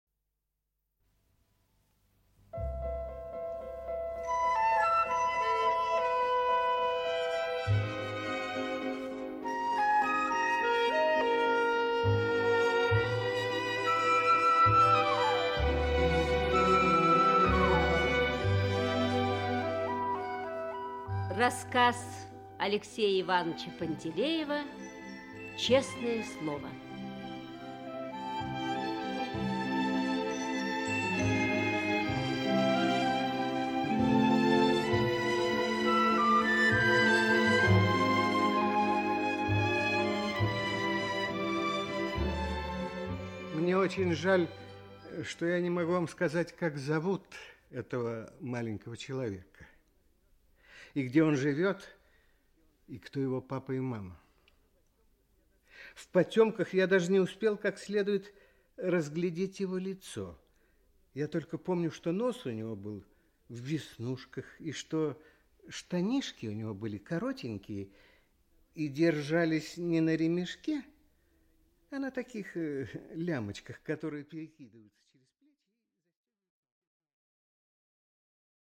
Аудиокнига Честное слово | Библиотека аудиокниг
Aудиокнига Честное слово Автор Леонид Пантелеев Читает аудиокнигу Актерский коллектив.